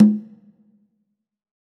Index of /90_sSampleCDs/EdgeSounds - Drum Mashines VOL-1/M1 DRUMS
MCONGA HI.wav